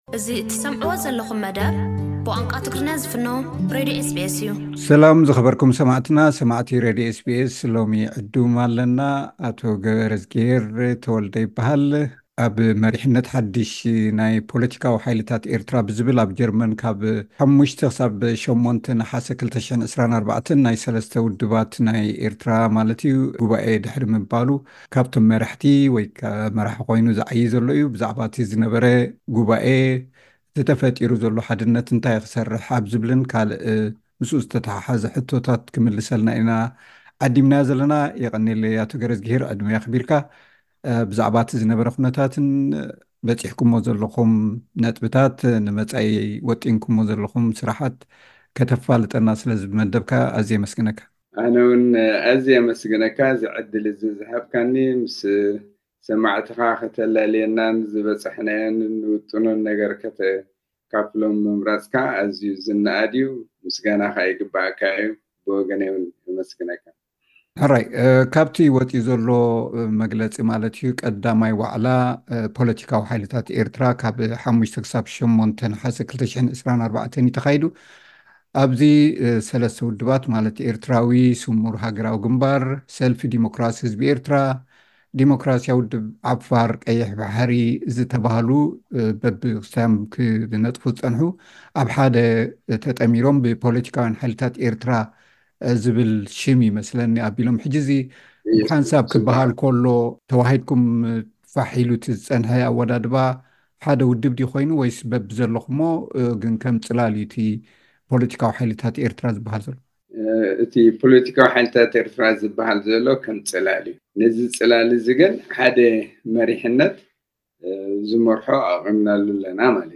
ዝርርብ